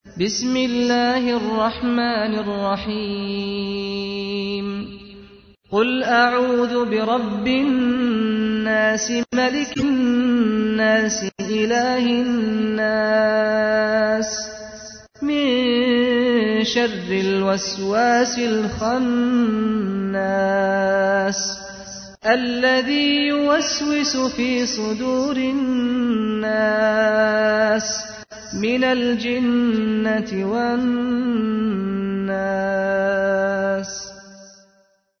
تحميل : 114. سورة الناس / القارئ سعد الغامدي / القرآن الكريم / موقع يا حسين